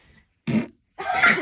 Furz Téléchargement d'Effet Sonore
Furz Bouton sonore